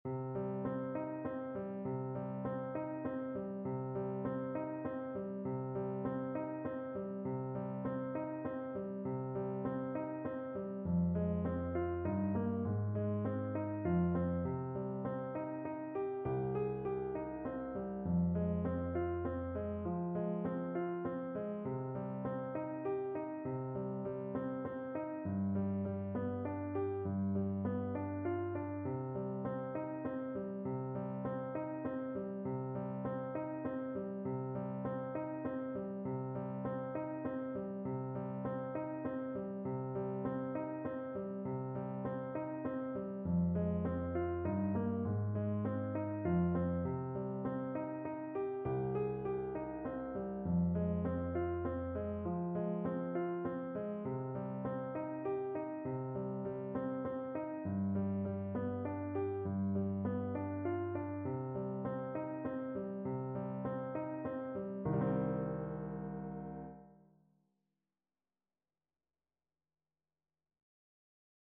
3/4 (View more 3/4 Music)
Moderato, gently